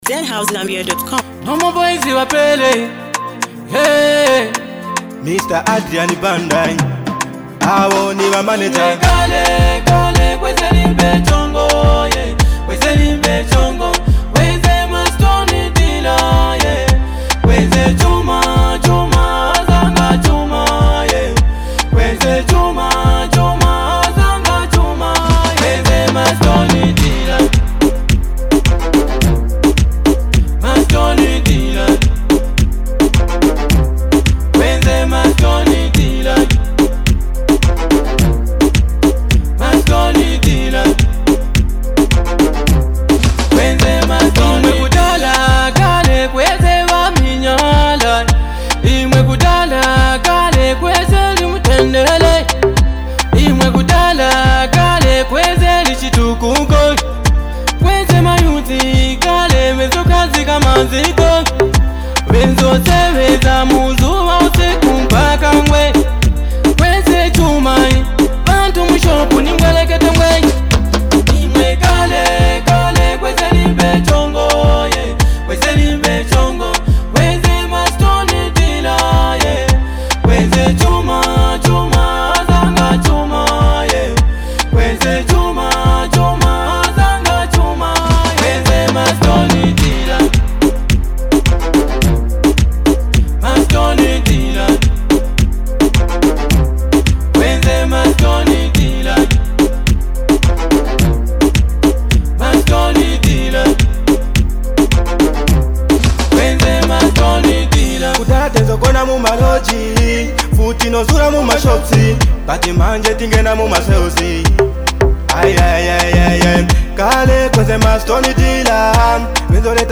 a nostalgic track
With smooth rhythms and heartfelt lyrics